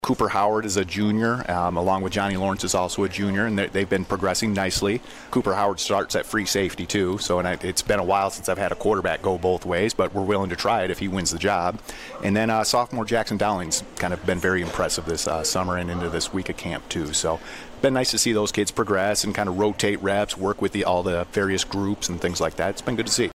96.5 The Cave and Lenawee TV held their annual Football Coaches Preview Show Sunday at Hometown Pizza at the Lakes…in Manitou Beach.